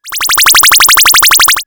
Shoot03.wav